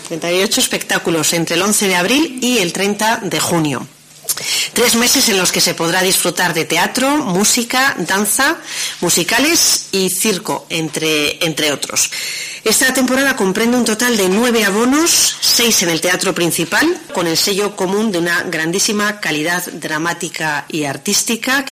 Sonia Díaz de Corcuera, edil de Cultura de Vitoria sobre la programación teatral de primavera